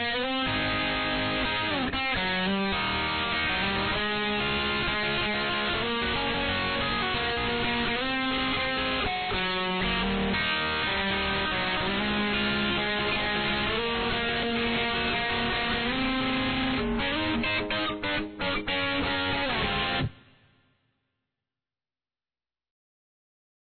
This song is tuned to Eb, Ab, Db, Gb, Bb, Eb (all flats)
Here is Guitar I by itself: